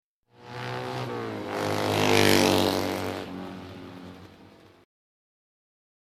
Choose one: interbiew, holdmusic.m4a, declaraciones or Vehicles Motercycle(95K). Vehicles Motercycle(95K)